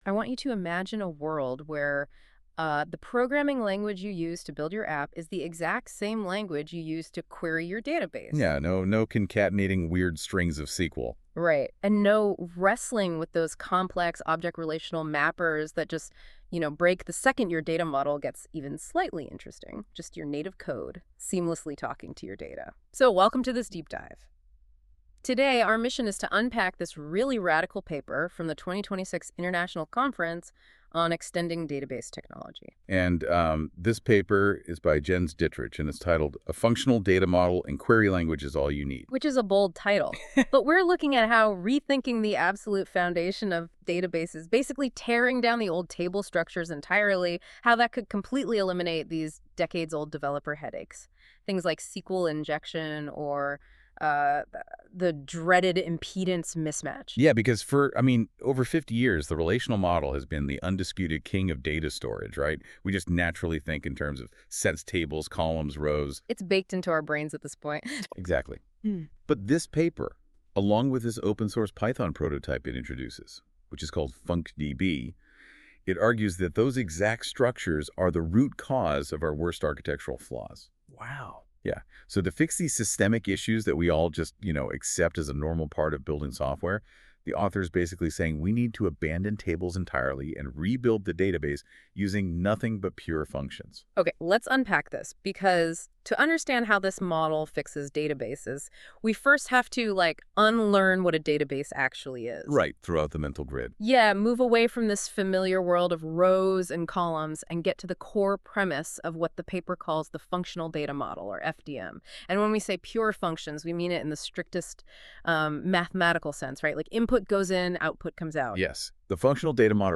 Note: Both the podcast audio and script were generated with AI assistance.